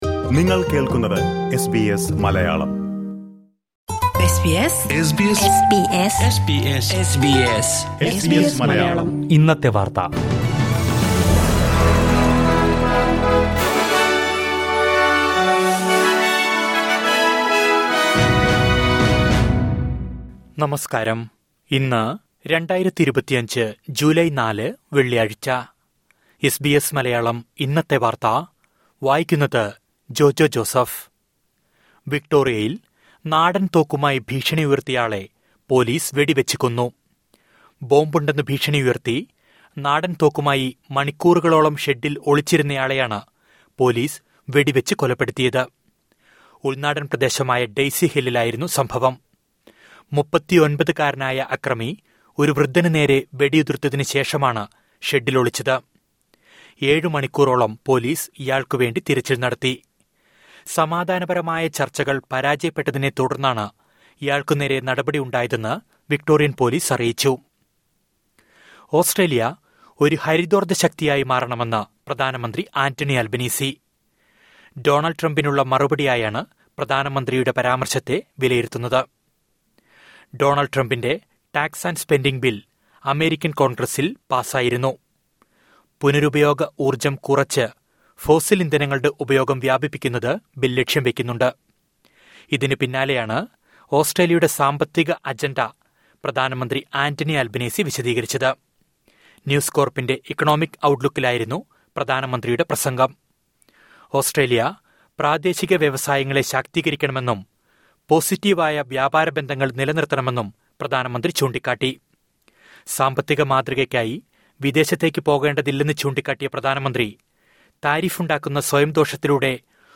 2025 ജൂലൈ നാലിലെ ഓസ്‌ട്രേലിയയിലെ ഏറ്റവും പ്രധാന വാര്‍ത്തകള്‍ കേള്‍ക്കാം...